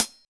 Percs
Perc (159).wav